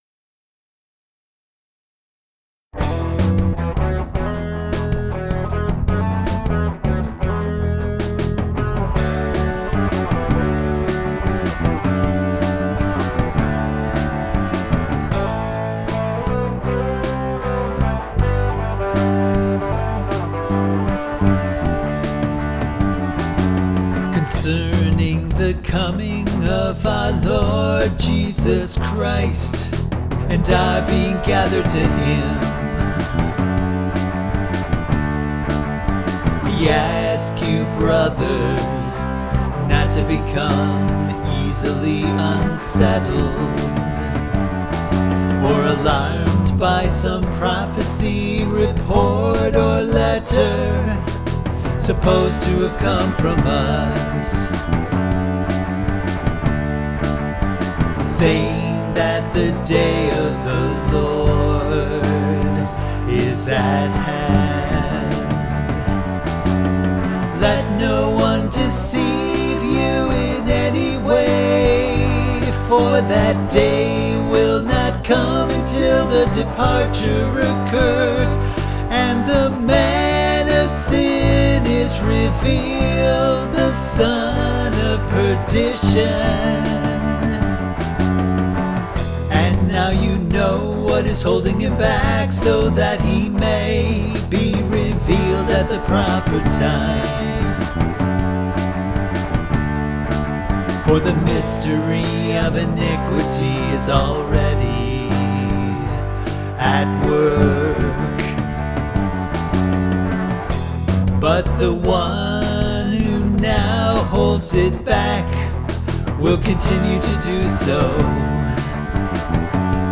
Vocals, Guitars, Bass